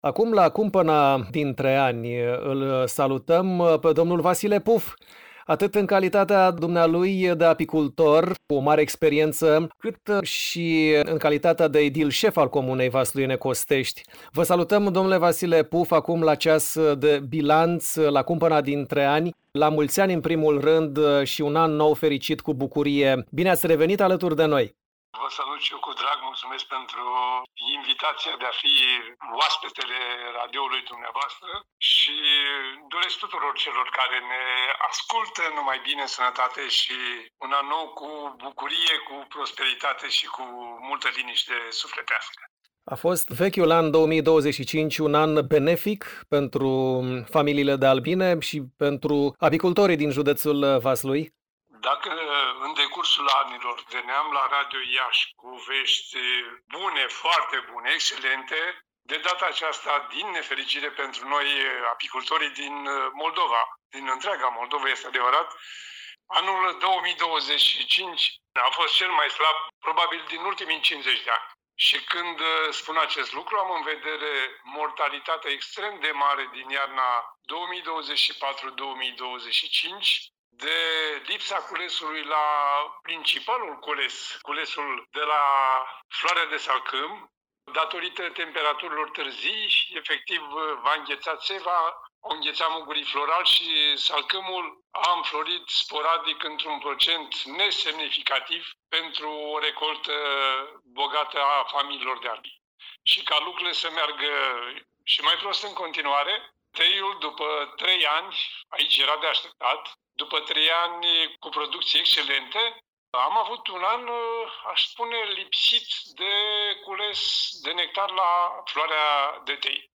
Realitățile cu care se confruntă crescătorii de albine din județul Vaslui, realizările deosebite din 2025 pentru comuna vasluiană Costești dar și așteptările pentru Noul An 2026 sunt dezvăluite de Vasile Puf, apicultor cu o mare experiență și edil șef al localității amintite, astăzi, vineri, 2 ianuarie 2026, în intervalul orar 13:25 – 13:40, la ”Pulsul […]
Sursă: Vasile Puf, apicultor cu o mare experiență și edil șef al comunei vasluiene Costești.